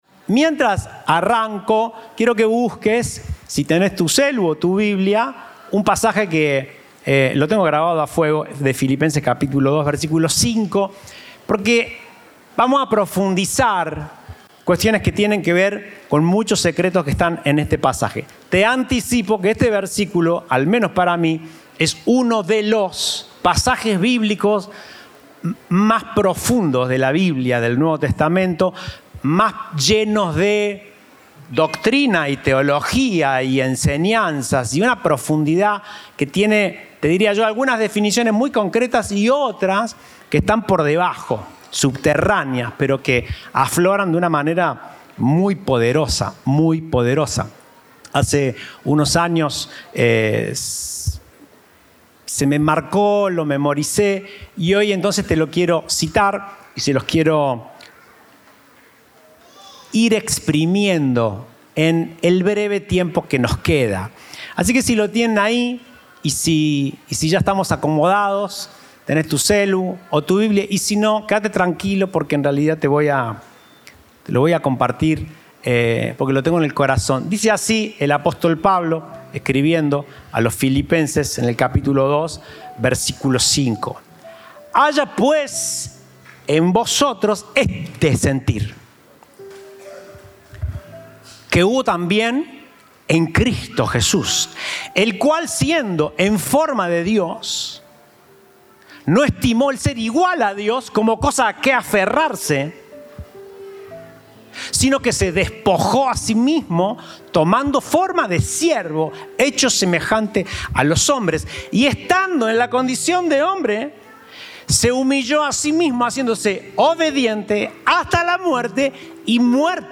Compartimos el mensaje del Domingo 18 de Febrero de 2024